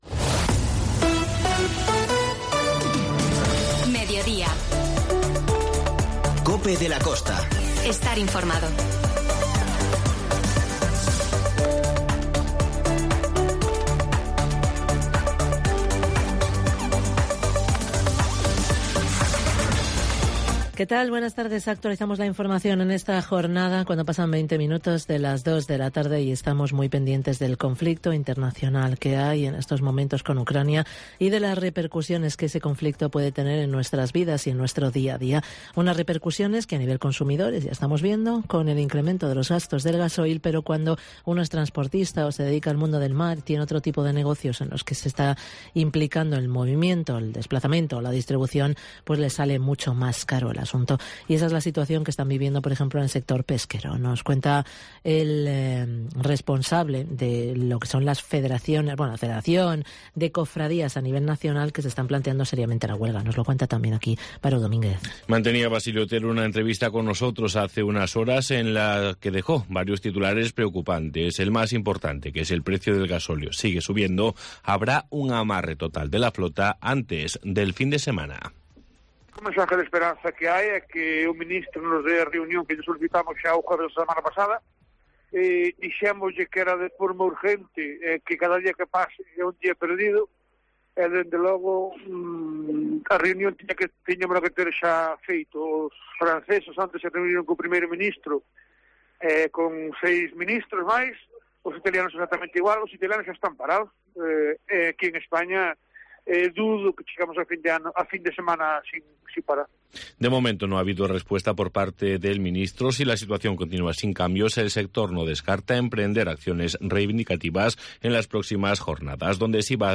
INFORMATIVO Mediodía en la Costa